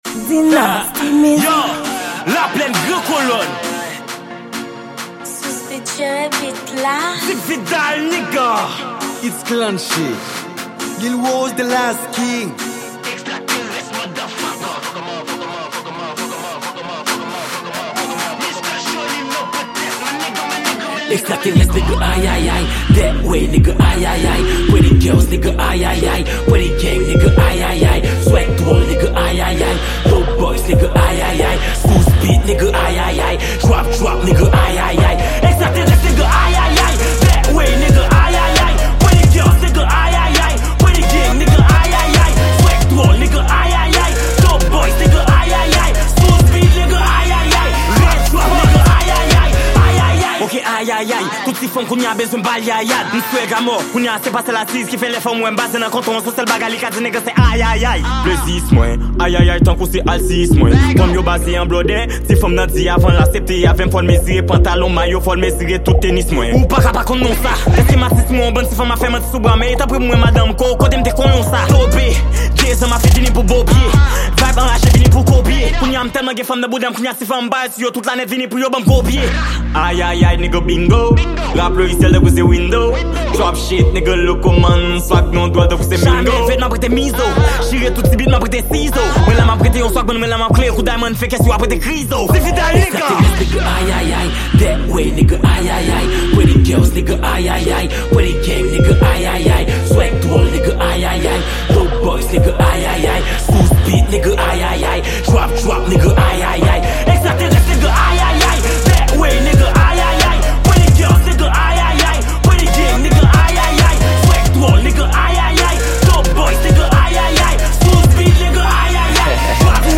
Genre: Trap